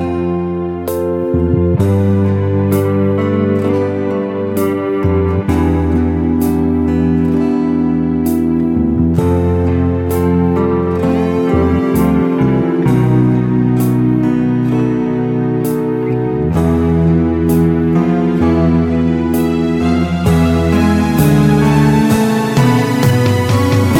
Minus Guitars Pop (1970s) 3:33 Buy £1.50